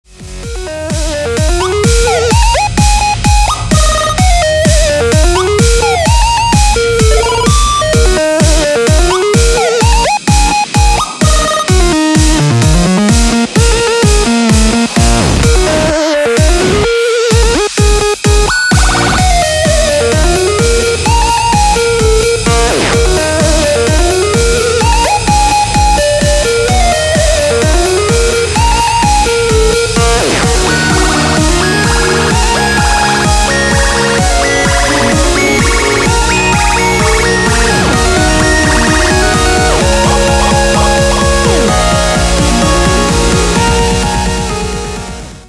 • Качество: 320, Stereo
без слов
Dubstep
Стиль: Electro house